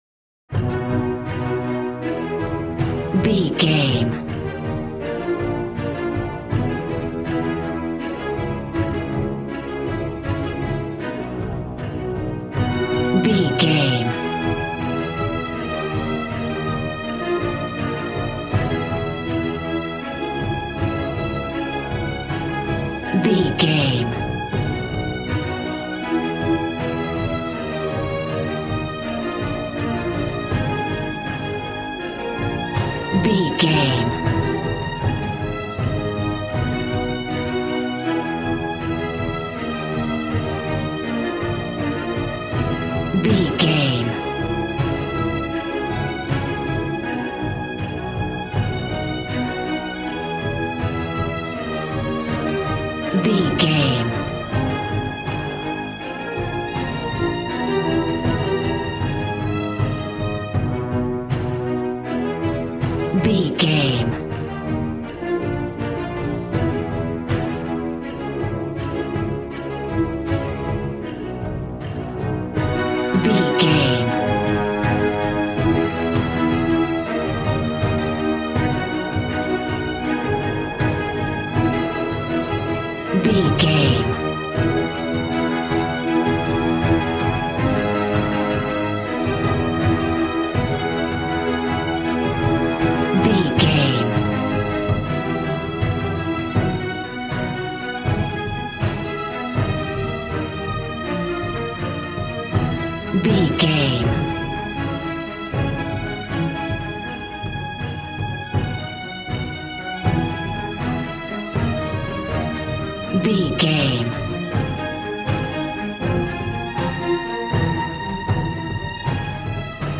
Aeolian/Minor
A♭
strings
violin
brass